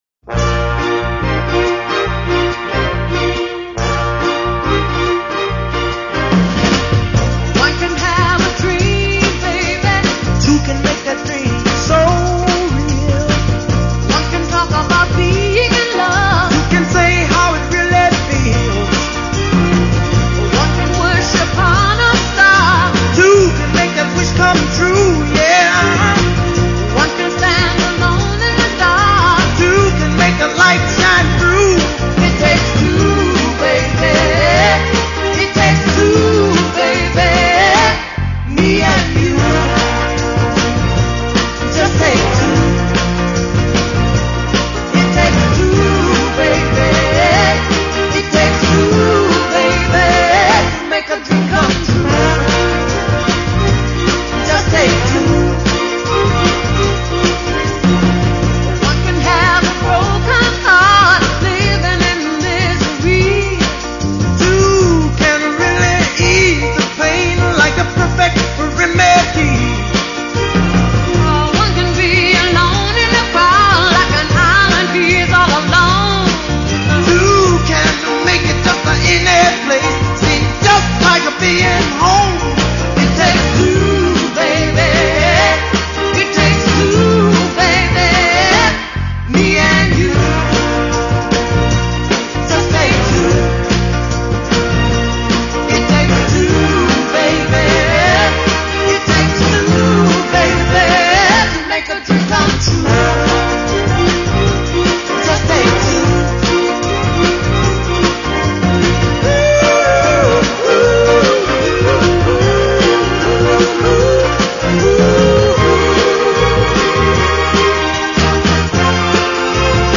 Une petite chanson, ça vous dit ?!